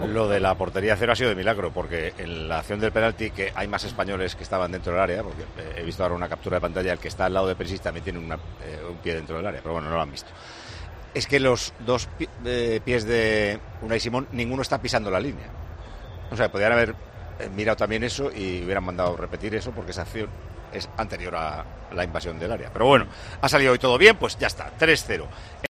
Paco González opina sobre la jugada del penalti de Croacia
Paco González, al término del choque, daba su opinión sobre esta acción: "La portería a cero ha sido de milagro, había más jugadores españoles dentro del área. El que estaba al lado de Perisic estaba también dentro del área".